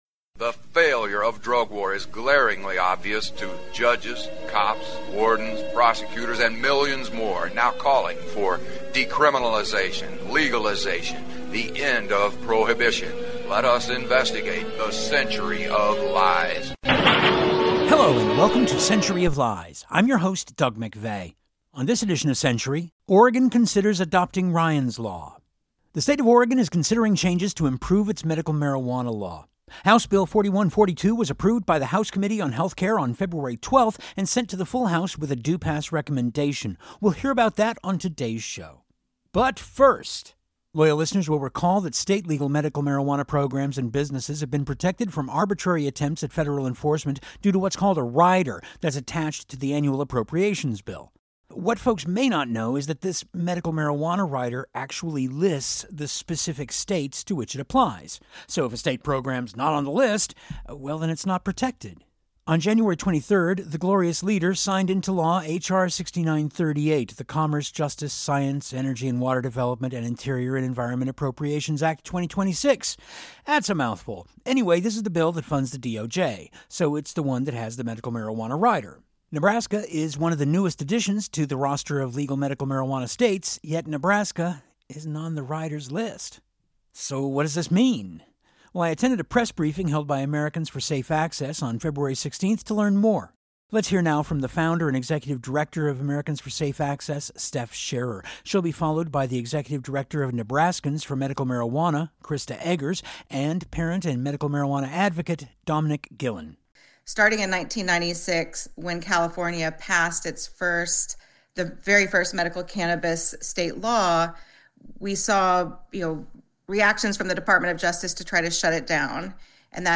We hear testimony from the Committee’s February 10 public hearing on that measure including from the bill’s lead sponsor, OR State Rep.